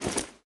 ui_interface_50.wav